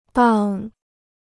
档 (dàng): official records; grade (of goods).